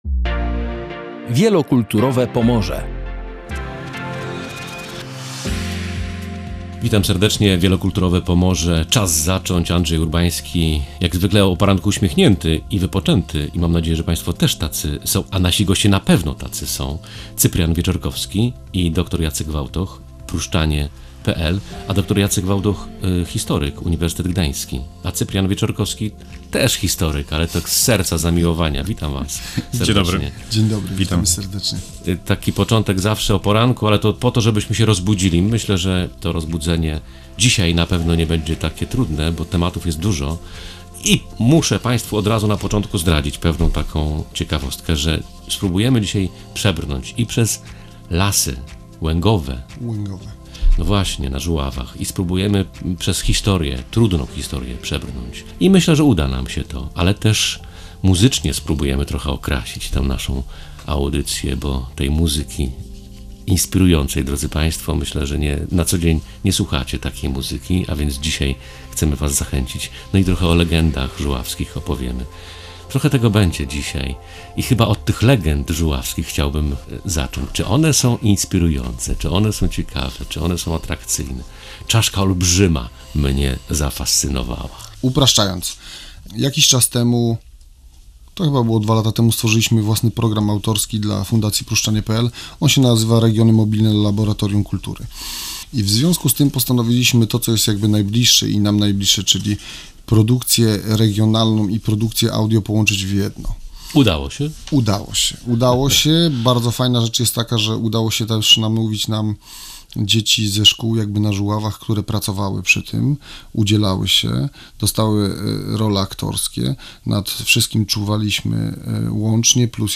W audycji wsłuchujemy się także w dźwięk lasów łęgowych na Żuławach.